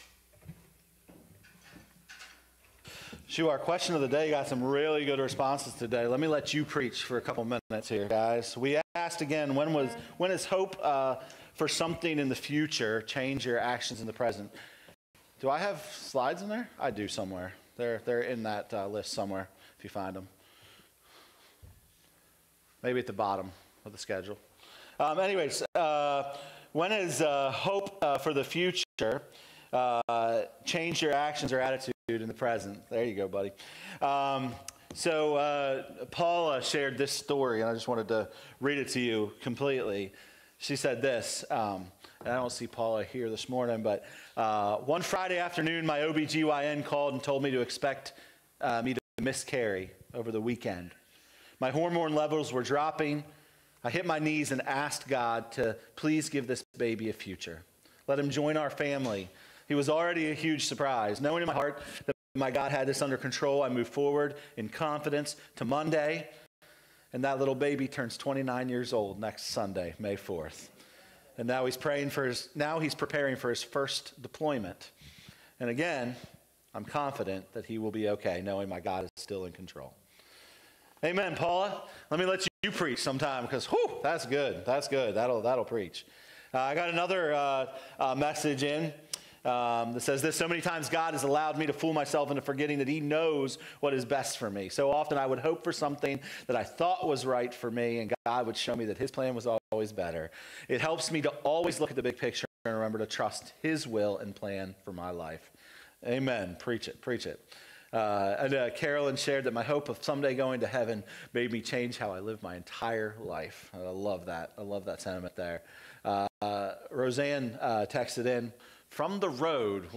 All Sermons , Revealed Book Revelation Watch Listen Save The vision of the New Heavens and New Earth gives us a hope that strengthens us now. Revelation doesn’t end in destruction—it ends in renewal.